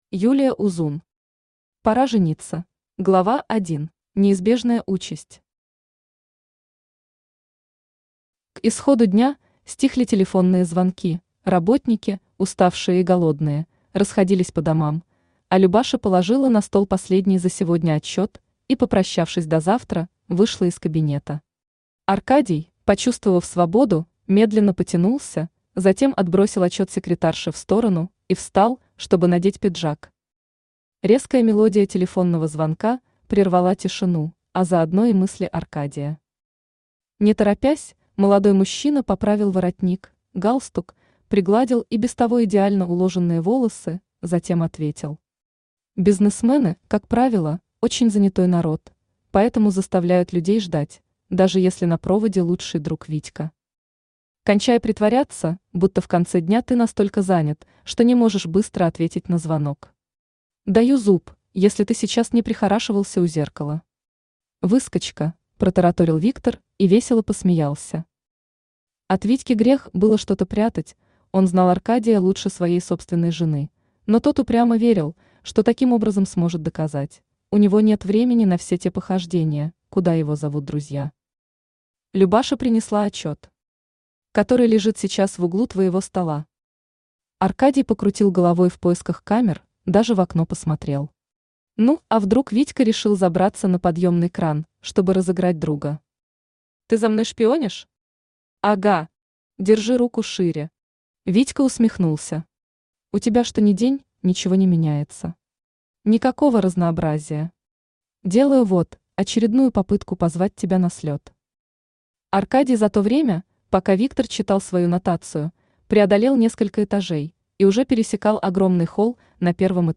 Аудиокнига Пора жениться | Библиотека аудиокниг
Aудиокнига Пора жениться Автор Юлия Узун Читает аудиокнигу Авточтец ЛитРес.